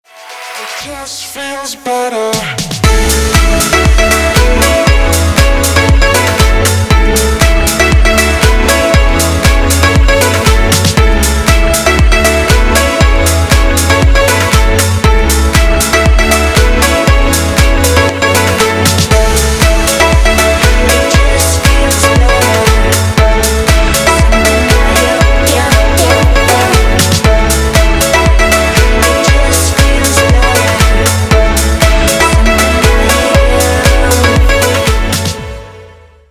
progressive house
vocal